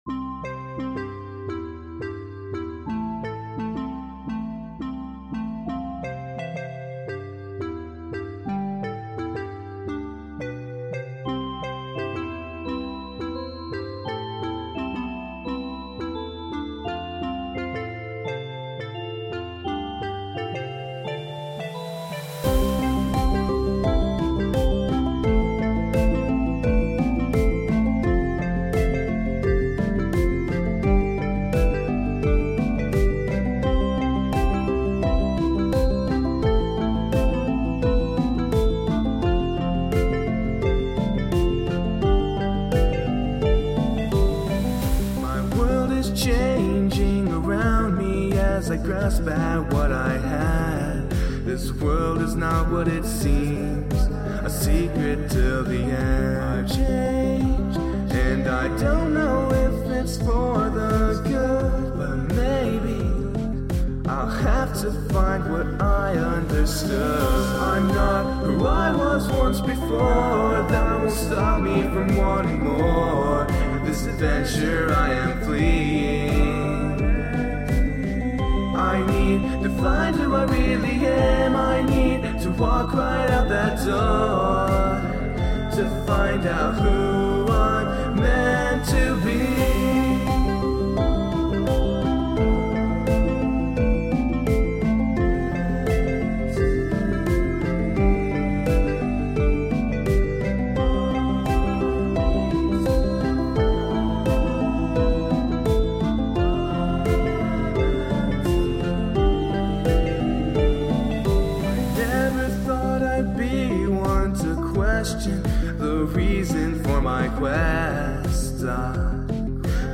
I'm also sort of sorry for the reeeeeally long ending.